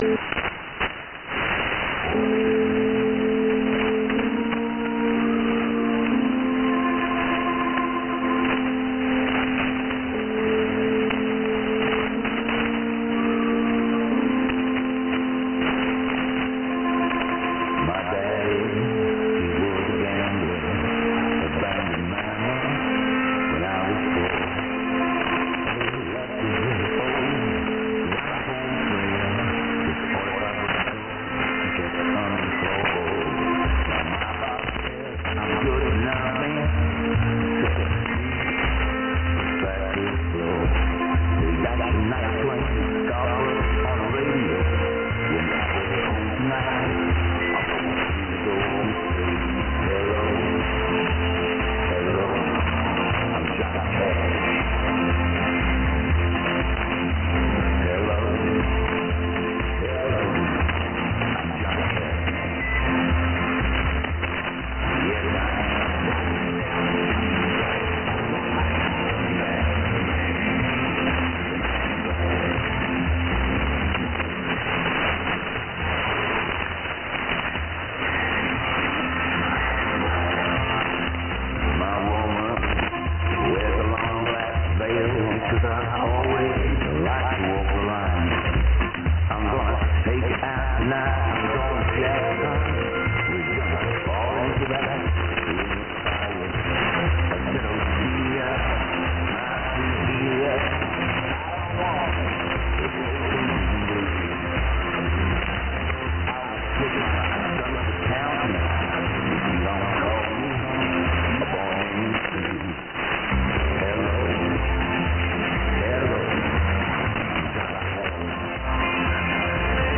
A very very late SDR recording catch!